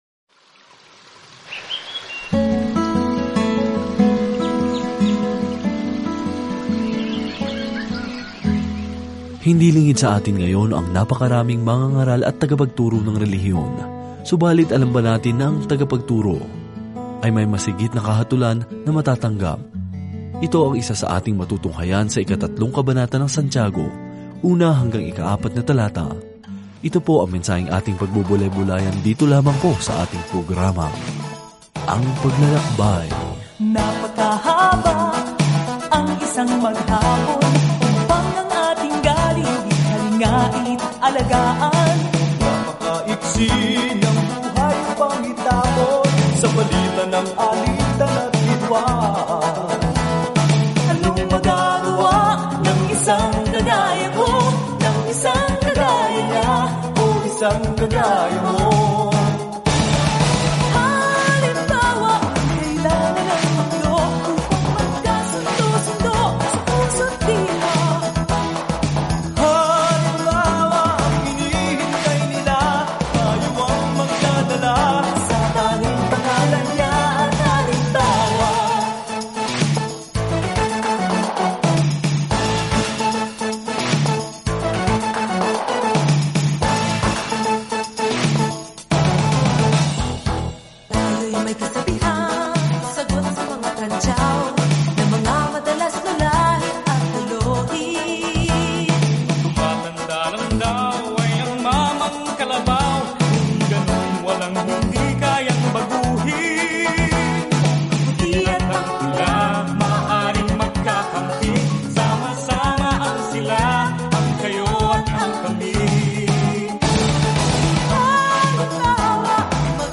Banal na Kasulatan Santiago 3:1-4 Araw 10 Umpisahan ang Gabay na Ito Araw 12 Tungkol sa Gabay na ito Kung ikaw ay isang mananampalataya kay Jesu-Kristo, kung gayon ang iyong mga aksyon ay dapat na sumasalamin sa iyong bagong buhay; ilagay ang iyong pananampalataya sa pagkilos. Araw-araw na paglalakbay kay James habang nakikinig ka sa audio study at nagbabasa ng mga piling talata mula sa salita ng Diyos.